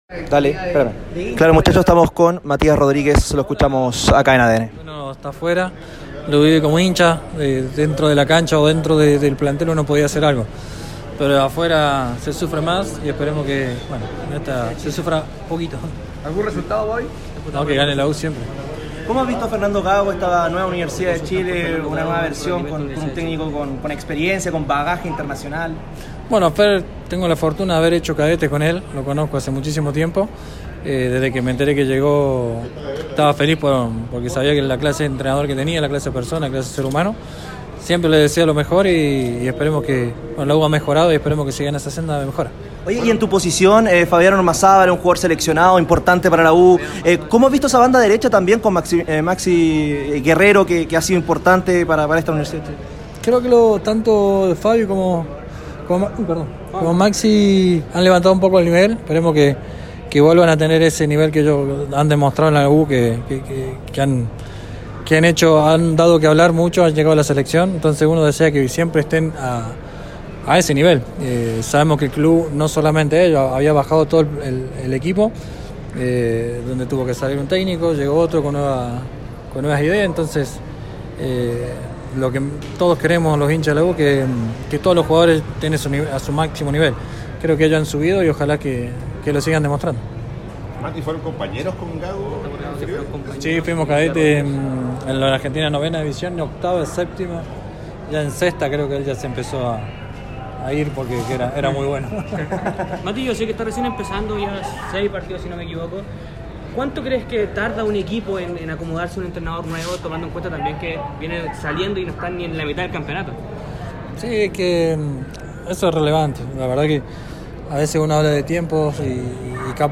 En su llegada al Estadio Nacional para el Clásico Universitario, Matías Rodríguez conversó con los micrófonos de ADN Deportes, donde recordó su pasado con el actual entrenador de la U.